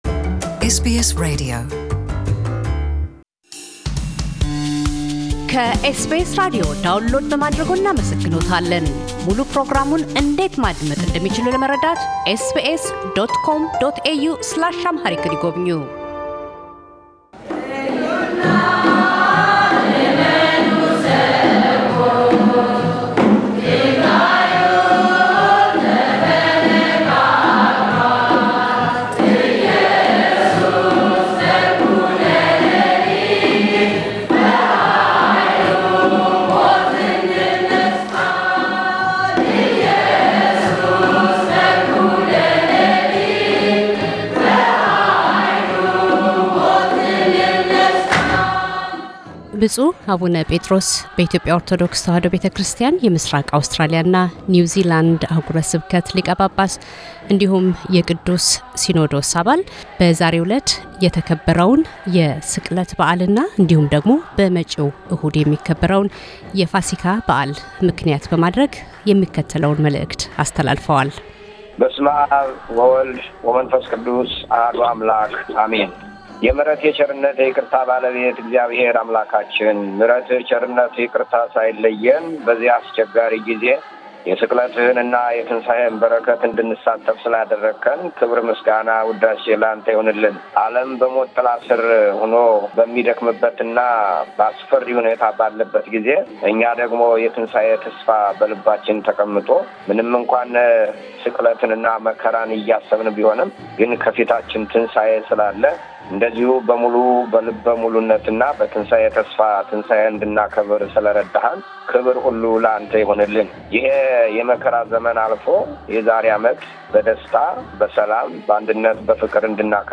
ብፁዕ አቡነ ጴጥሮስ - የምሥራቅ አውስትራሊያና ኒውዝላንድ አሕጉረ ስብከት ሊቀ ጳጳስና የቅዱስ ሲኖዶስ አባል፤ የበዓለ ትንሣኤ መንፈሳዊ መልዕክታቸውን ለእምነቱ ተከታዮች ያስተላልፋሉ።